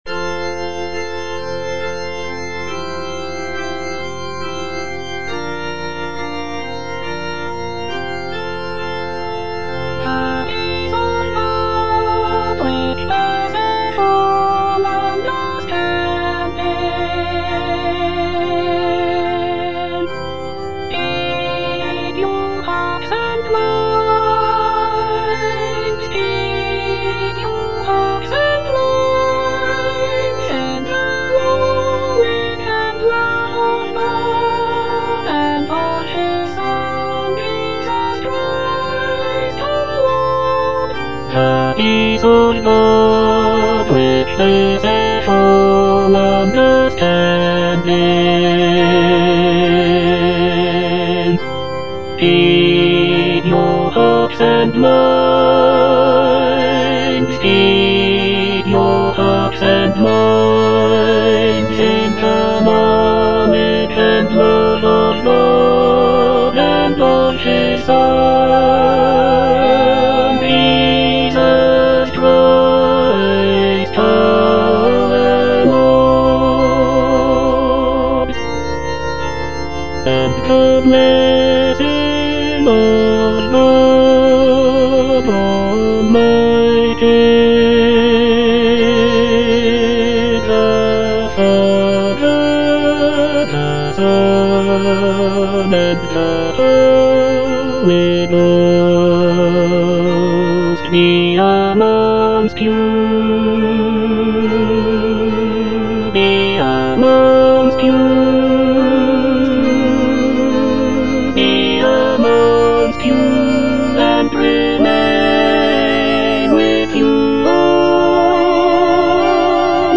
Tenor (Emphasised voice and other voices)
choral anthem